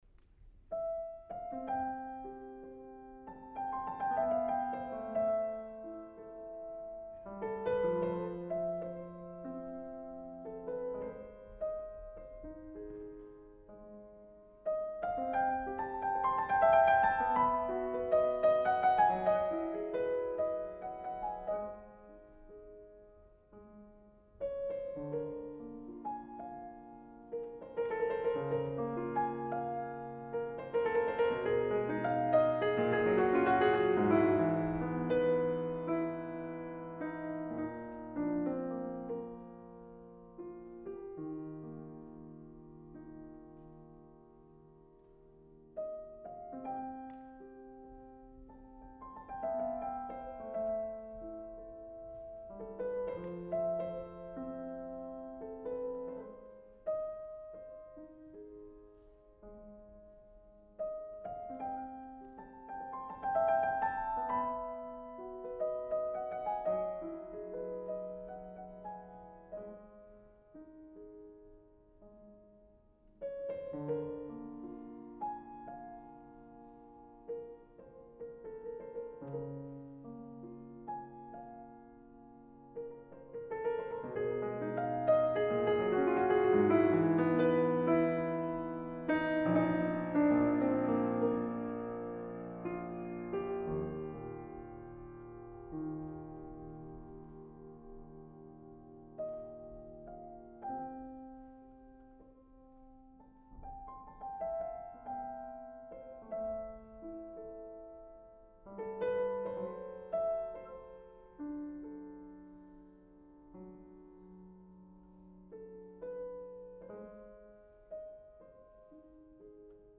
Но зашли его фортепианные минималистические фортепианные багатели.